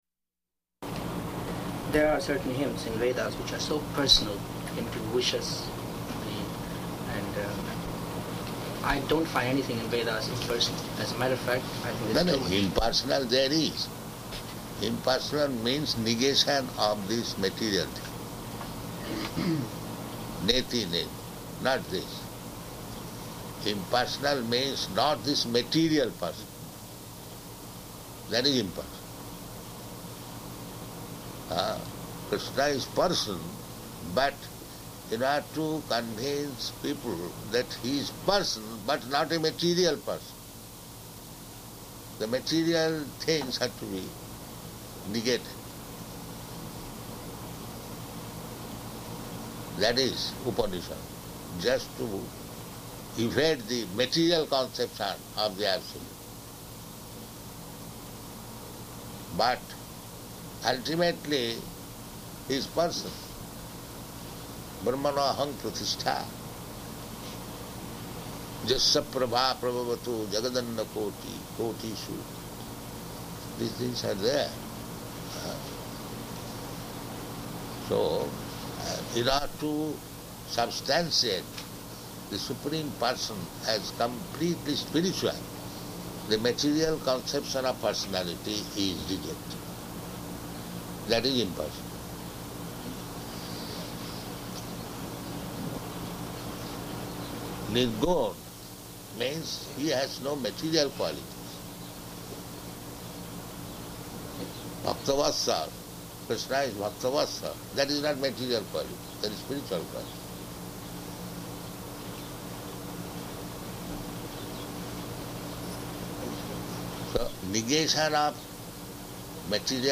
Type: Conversation
Location: Washington, D.C.